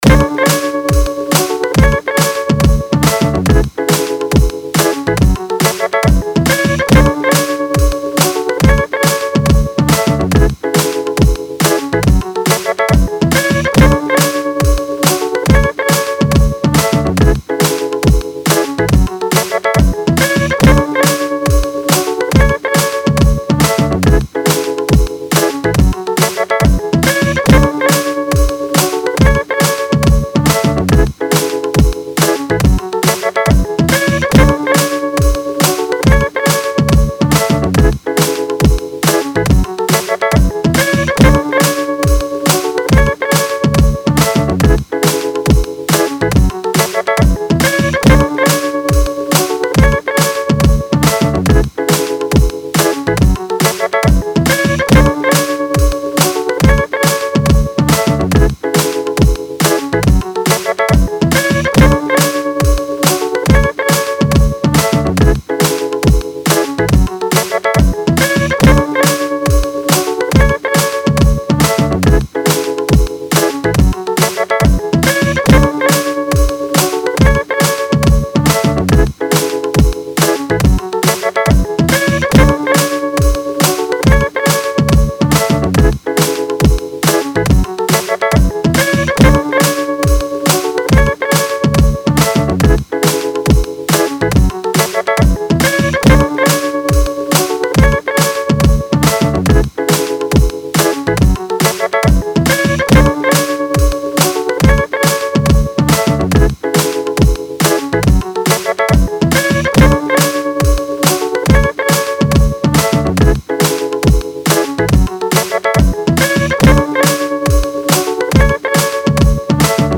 늘 이상한 비트올리는데 들어주셔서 감사합니다^.^// 컨셉은 비트가 신나는데 멜로디는 아름다운.. 제이름처럼 뷰티풀한 디스코 힙합입니다 ㅋㅋㅋㅋㅋㅋㅋㅋㅋㅋㅋㅋㅋㅋㅋㅋㅋㅋㅋㅋㅋㅋㅋㅋㅋㅋ 0 Scrap This!